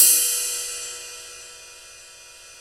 Index of /90_sSampleCDs/Roland - Rhythm Section/CYM_Rides 1/CYM_Ride menu
CYM PING RD1.wav